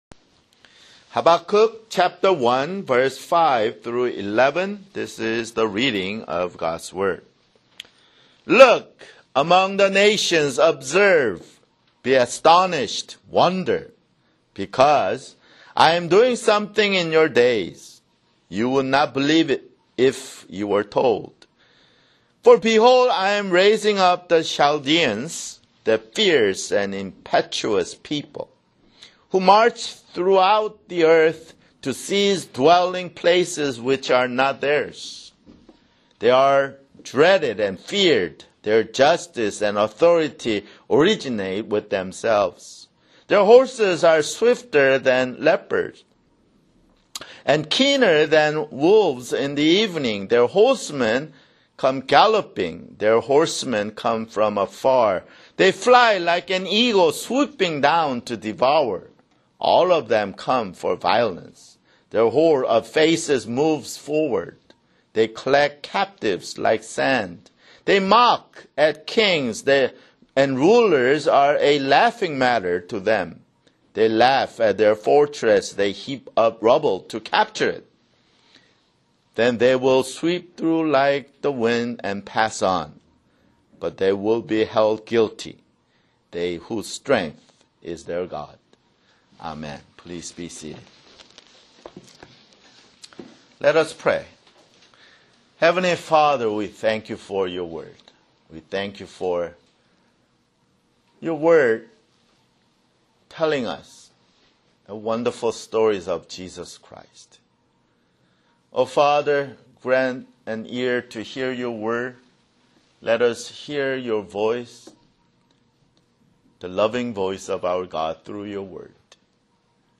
[Sermon] Habakkuk (4)